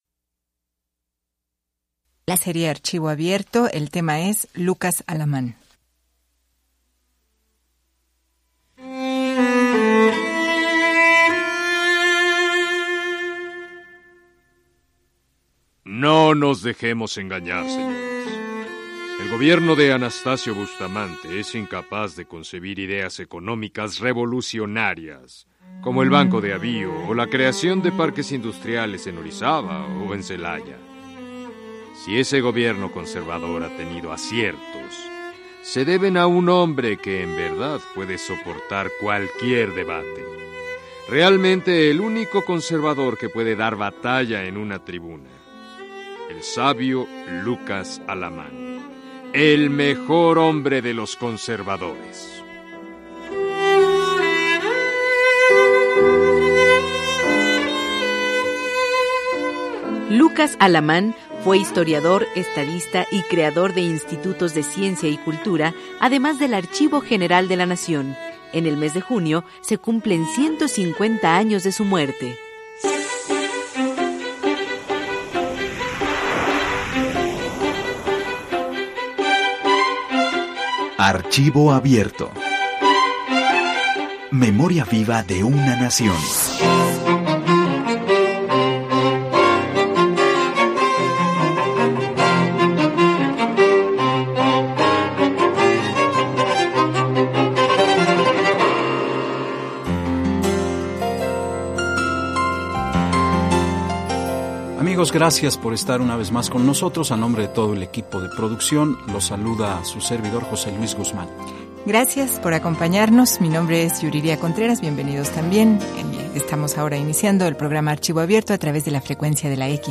Escucha una semblanza sobre el guanajuatense Lucas Alamán en el programa del Archivo General de la Nación, “Archivo Abierto”, transmitido en 2003.